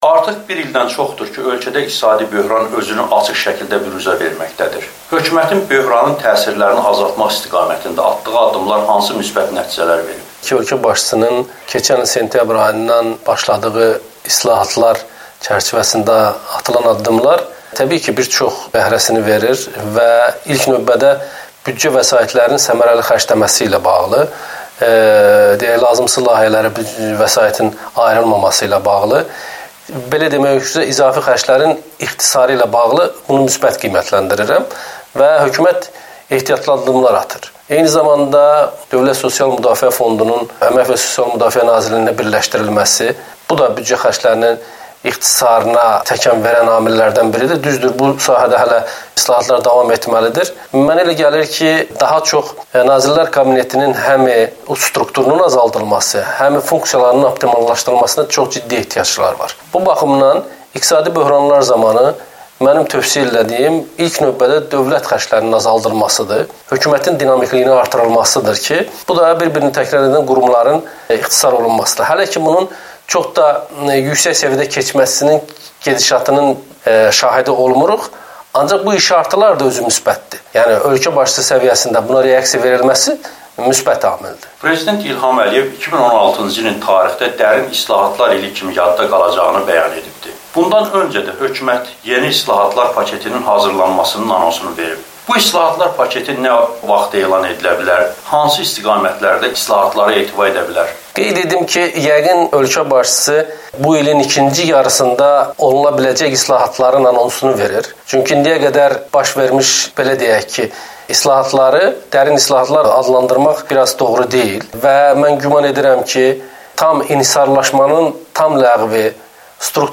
Amerikanın Səsinə müsahibəsi